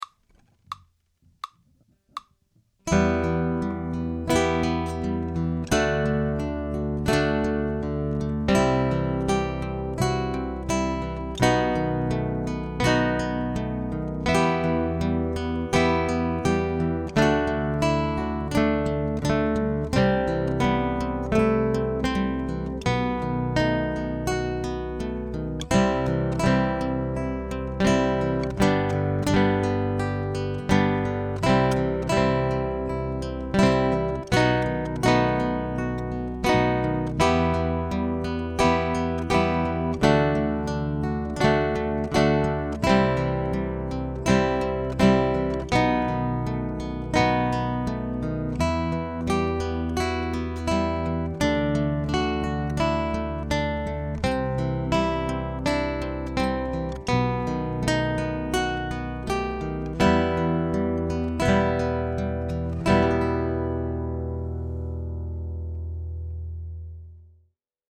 guitar ensembles in a variety of styles